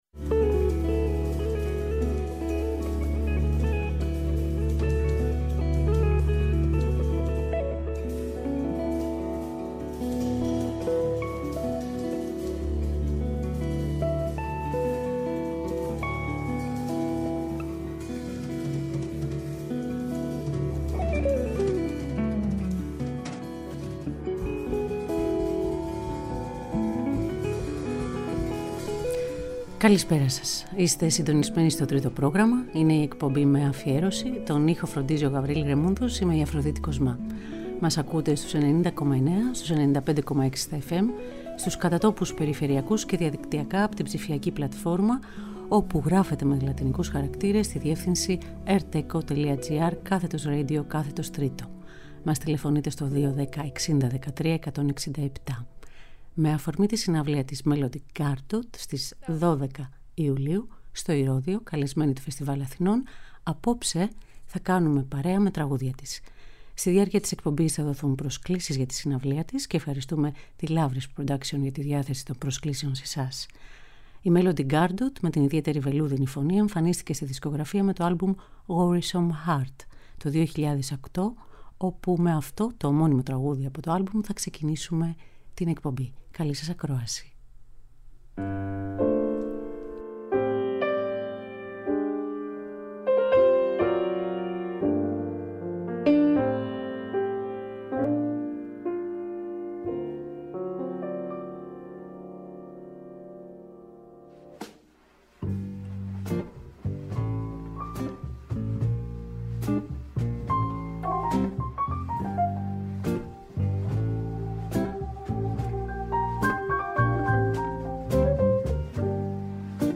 με τη βελούδινη φωνή
πιάνο, κιθάρα